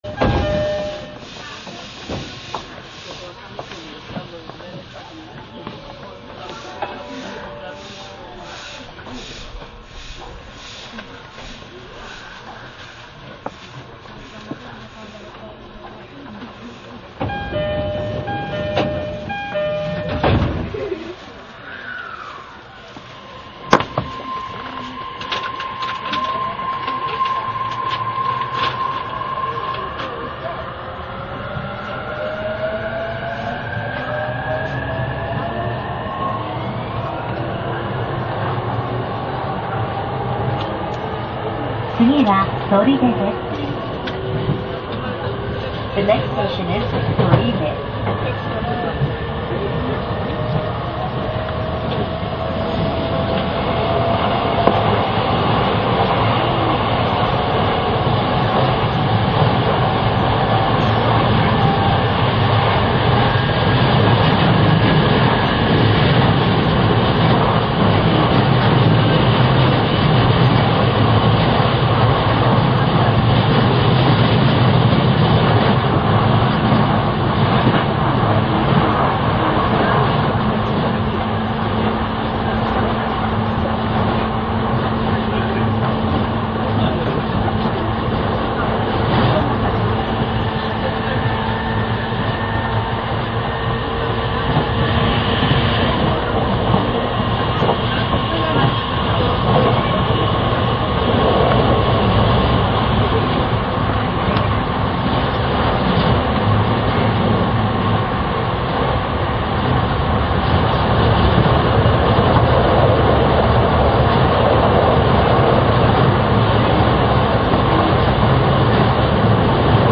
◎走行音Ａ
ドアが閉まってから、加速する音が 　走行音Ａは・・・
ヒュルヒュルー　クィーン　ブーン・・・